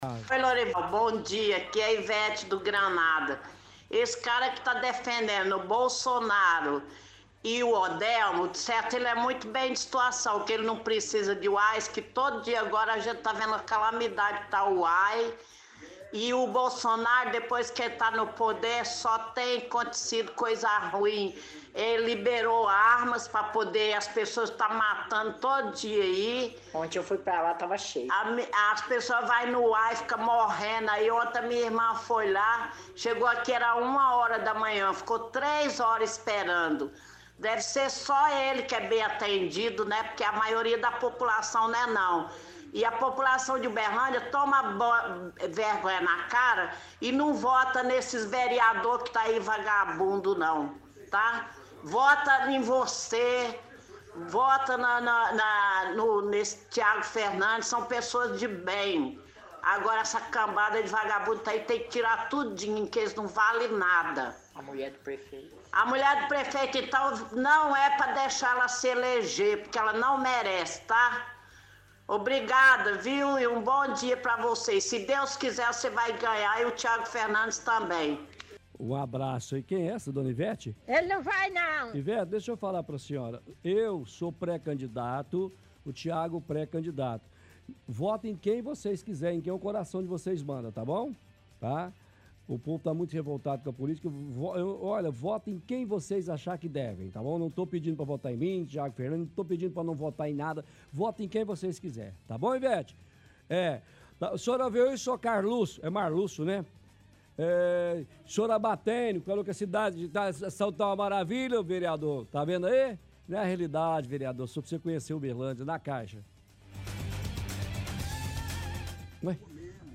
– Ouvinte reclama de demora e lotação em Unidades de Saúde.